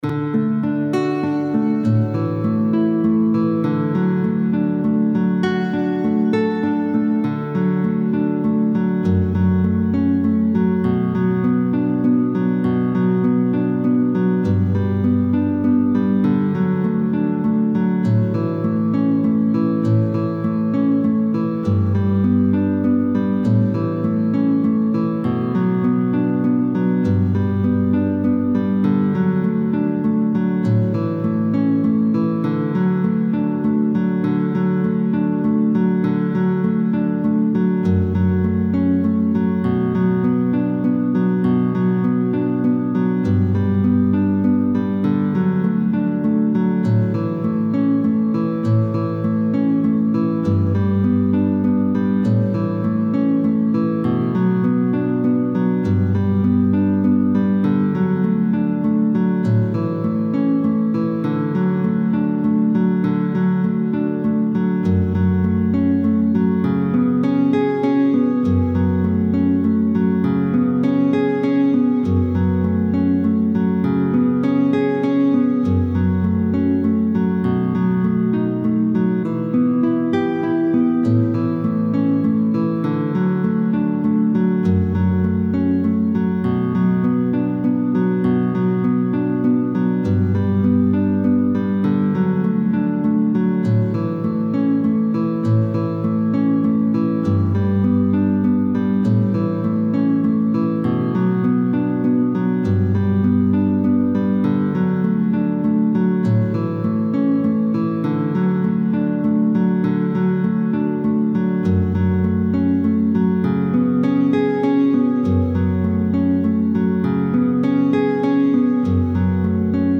Capodastre : 2ᵉ case